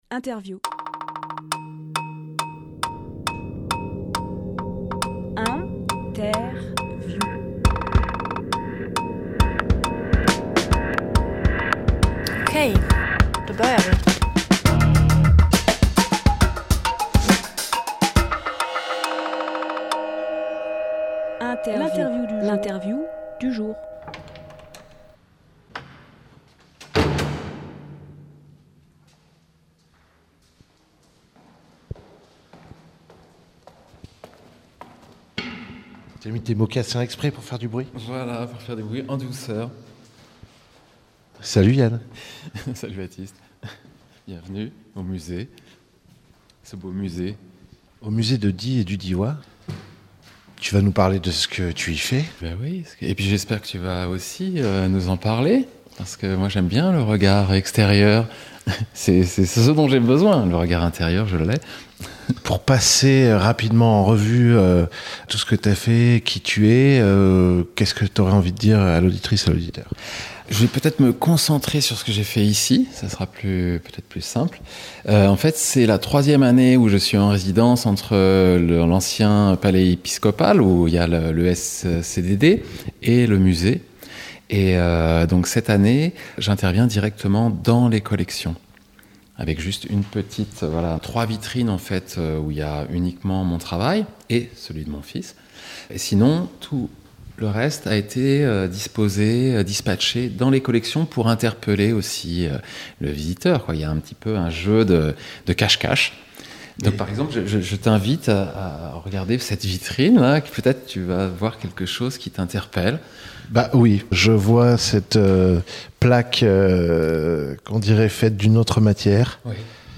Emission - Interview
Lieu : Musée de Die et du Diois